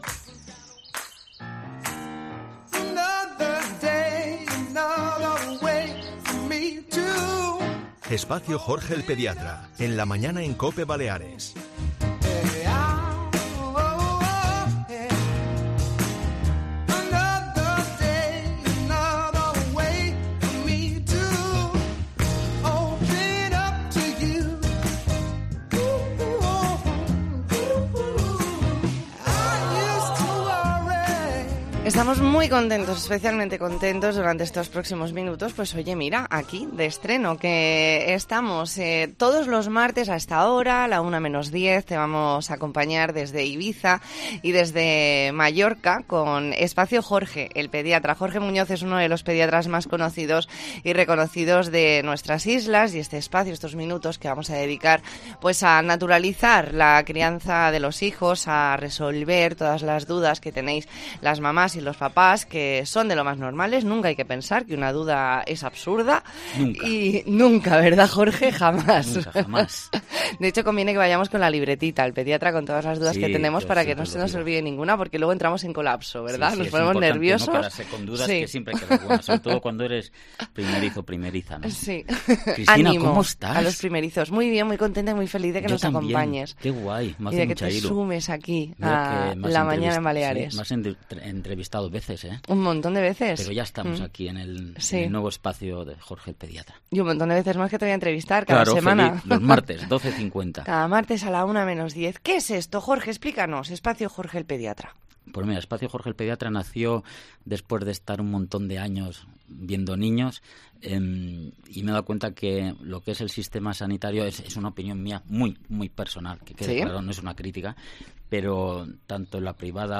ntrevista en La Mañana en COPE Más Mallorca, martes 27 de septiembre de 2022.